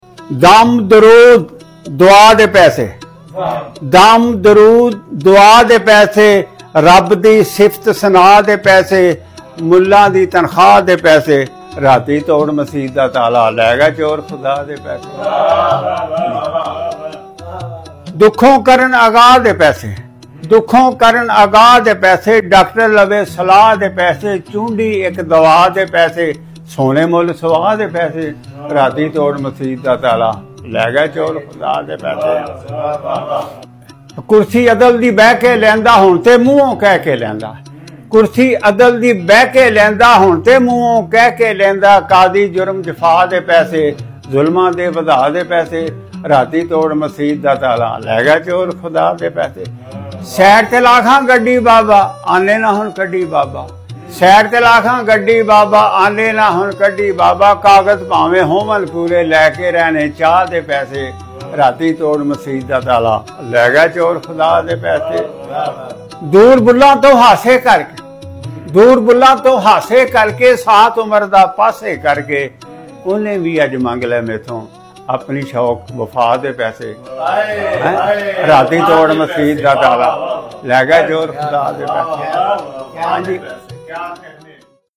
Latest Most Famous Funny Punjabi Poetry